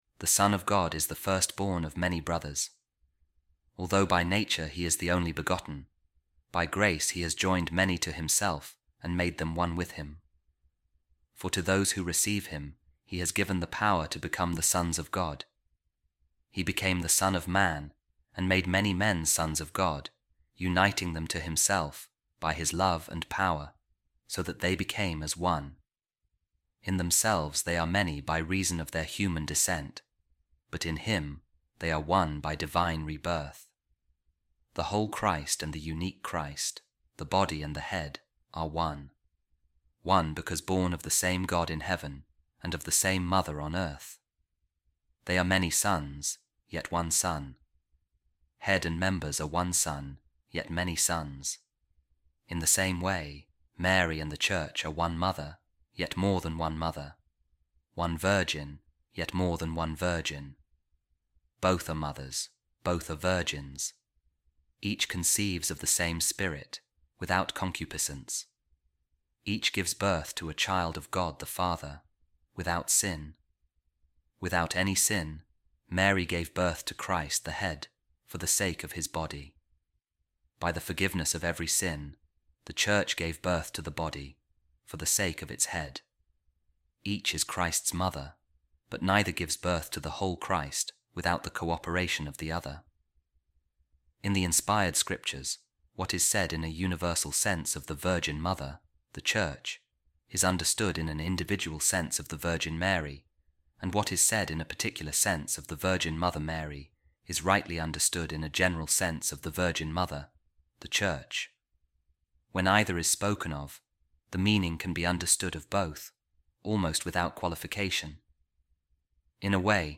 A Reading From A Sermon By Blessed Isaac Of Stella, Abbot | Mary And The Church